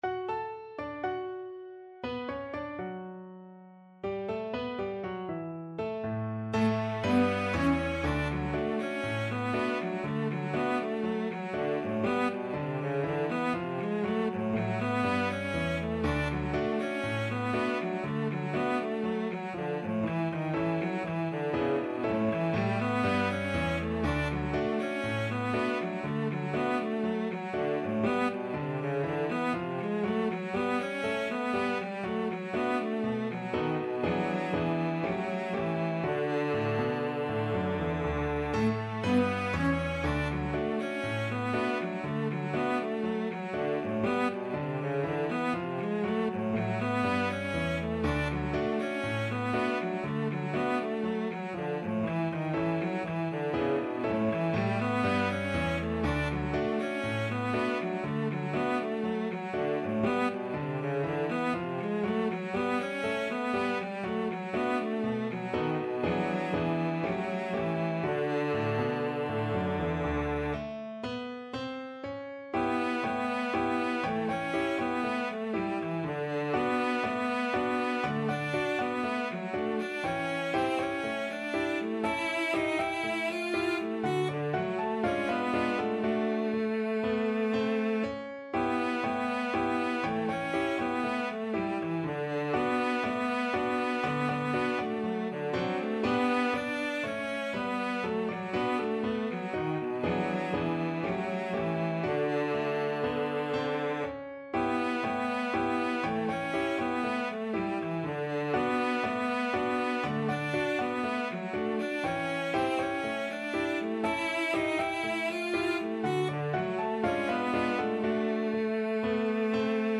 Classical Joplin, Scott Sunflower Slow Drag Cello version
Play (or use space bar on your keyboard) Pause Music Playalong - Piano Accompaniment Playalong Band Accompaniment not yet available transpose reset tempo print settings full screen
Cello
D major (Sounding Pitch) (View more D major Music for Cello )
~ = 60 INTRO Not fast
2/4 (View more 2/4 Music)
Classical (View more Classical Cello Music)